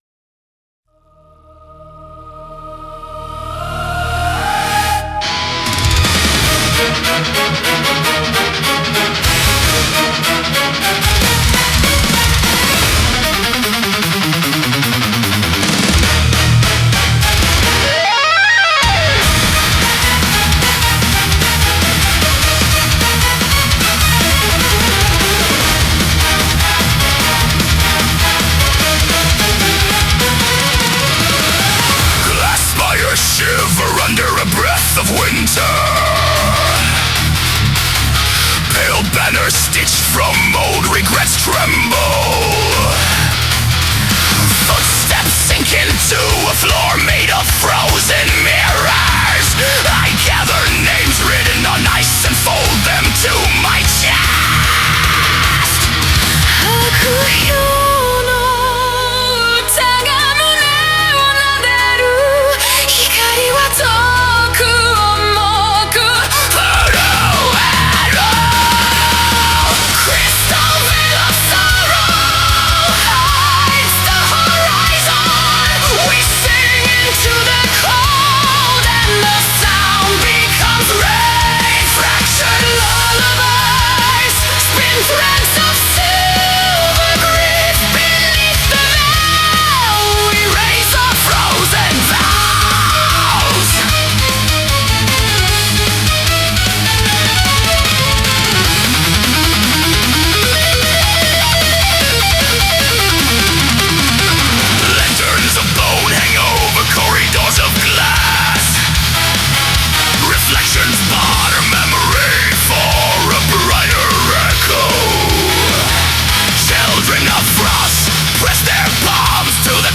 Symphonic Death Metal